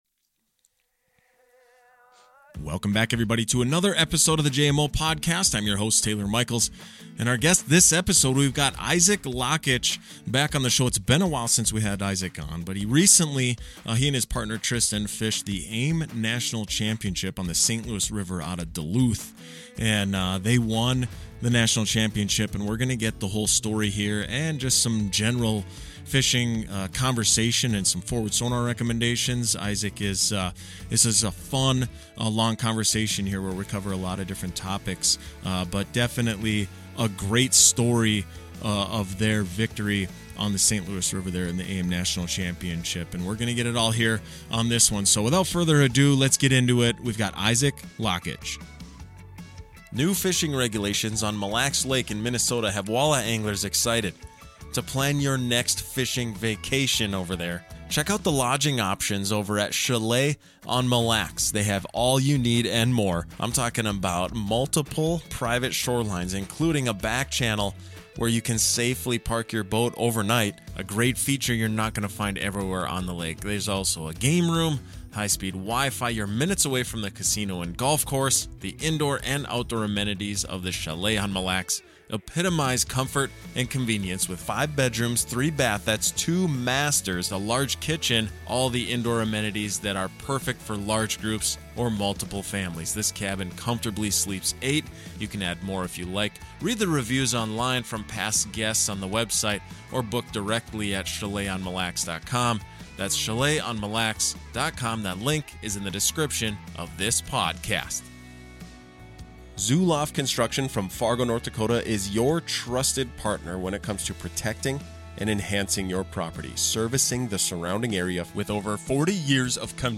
In this interview we get all the details on how this tournament unfolded for these two anglers.